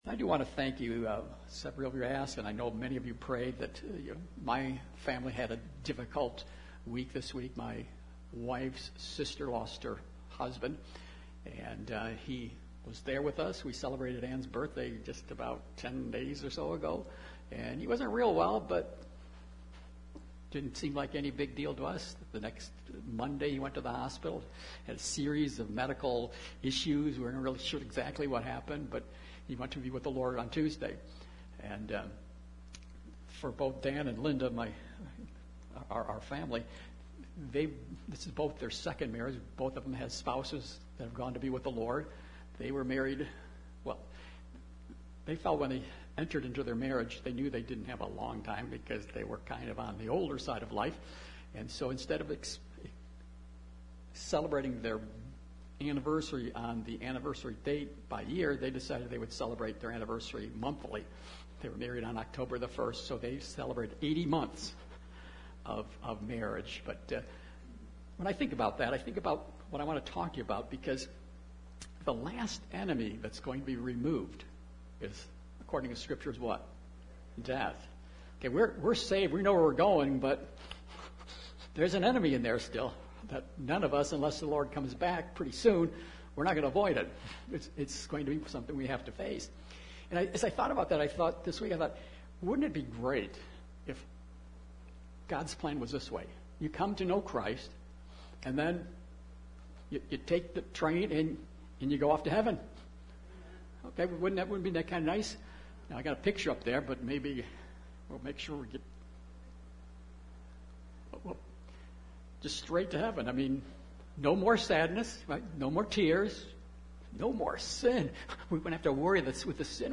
Jul 03, 2022 Called to Be Holy MP3 SUBSCRIBE on iTunes(Podcast) Notes Sermons in this Series 1 Peter 1: 14-16 Thank You, Peter!